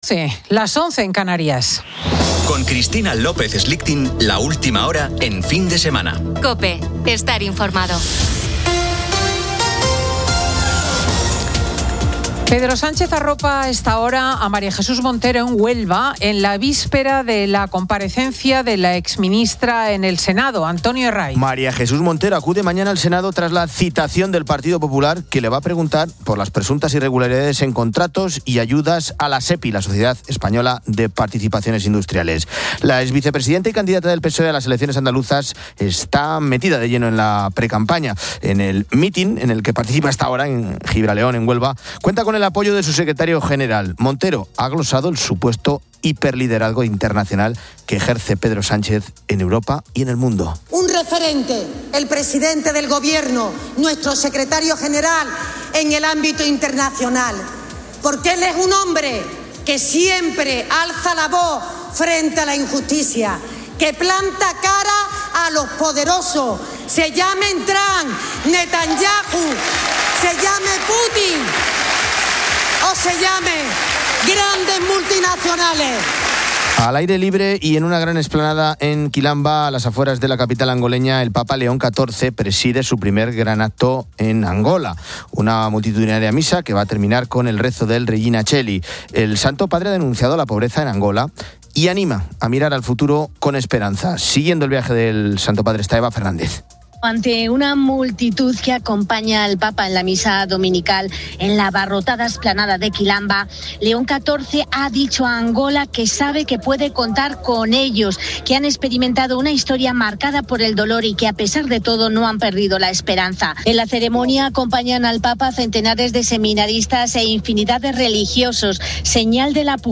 En Venezuela, los familiares de presos políticos cumplen cien días de vigilias, denunciando la falta de liberaciones y el trato cruel en las cárceles, a pesar de un proceso de amnistía. Además, el espacio ofrece una tertulia amena sobre el humor y los chistes, explorando su poder para mejorar el ánimo.